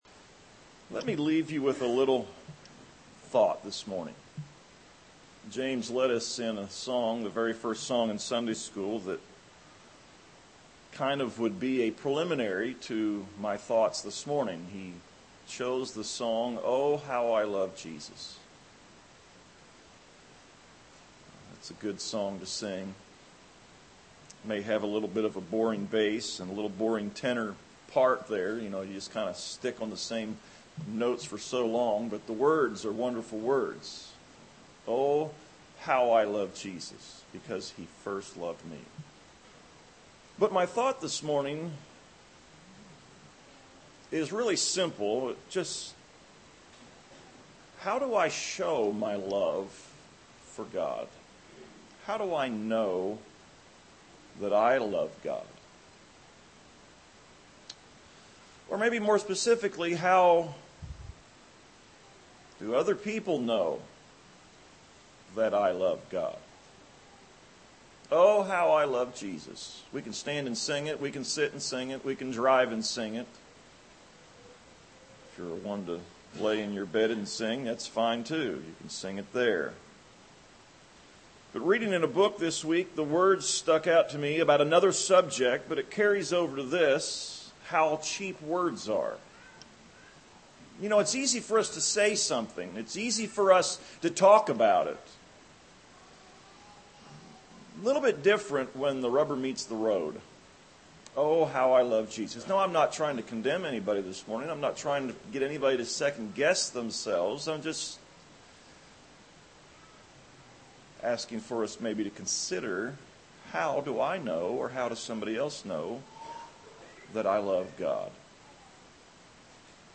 2015-3-1-am-sermon.mp3